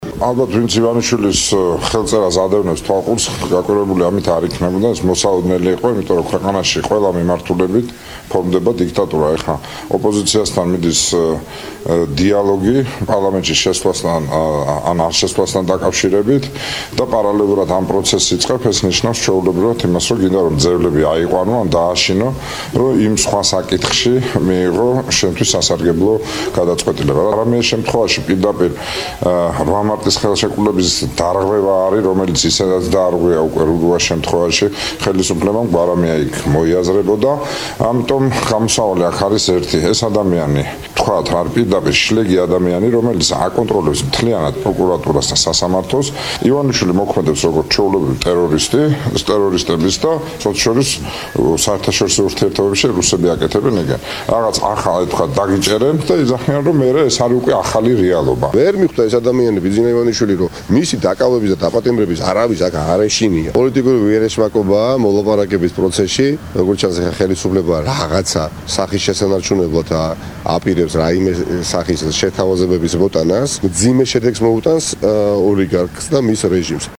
მოვისმინოთ გიგი უგულავას და ნიკა მელიას კომენტარები